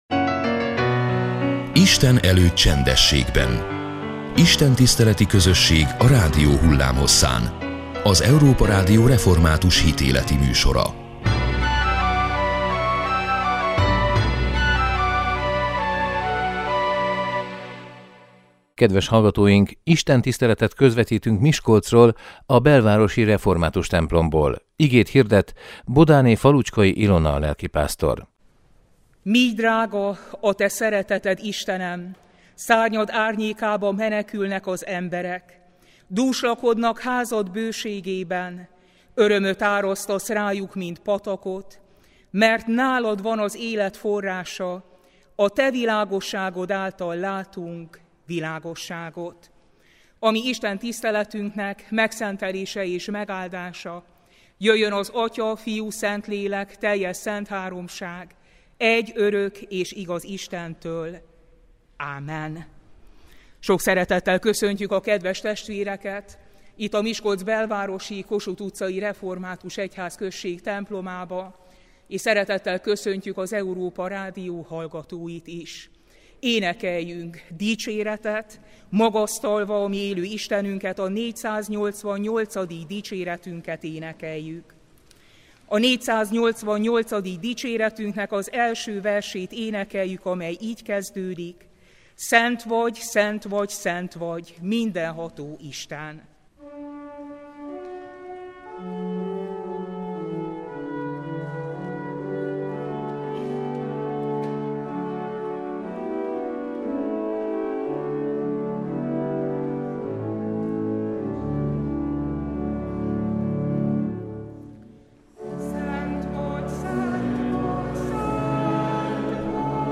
Istentiszteletet közvetítettünk Miskolcról, a belvárosi református templomból.